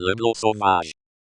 as they say in EMS